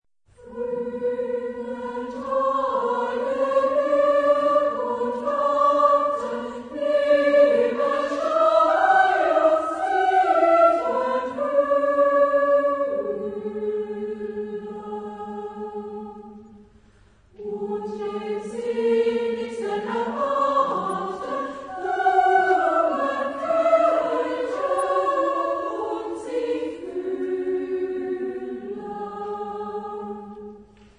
Epoque: 20th century
Type of Choir: SSAA  (4 children OR women voices )